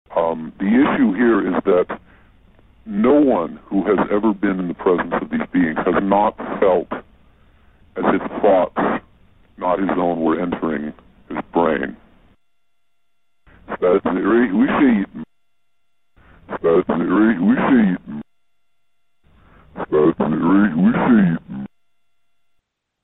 最大の難点は、 ヴォイスチェンジャーによって音声が聞きづらくなっていたことだった。